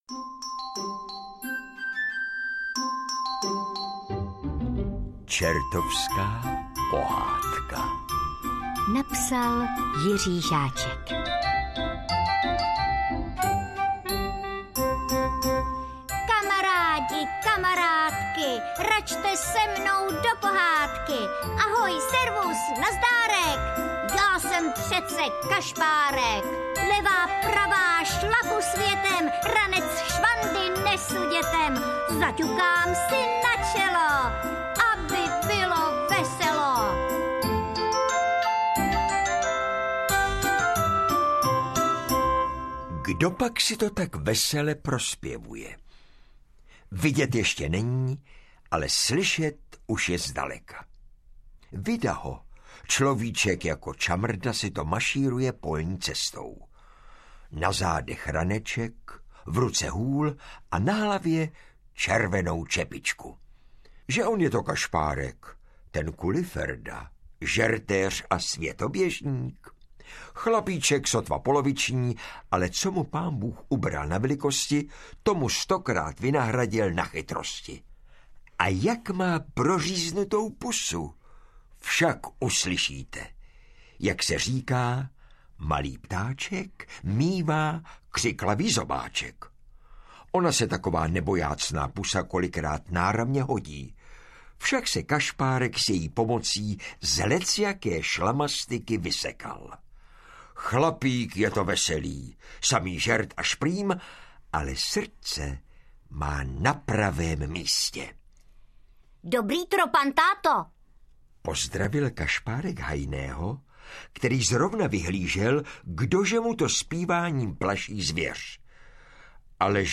Čertovská pohádka audiokniha
Ukázka z knihy
Jestlipak kašpárek s Honzou princeznu nakonec z pekla dostanou?Vyprávějí, hrají a zpívají Jan Přeučil a Eva Hrušková.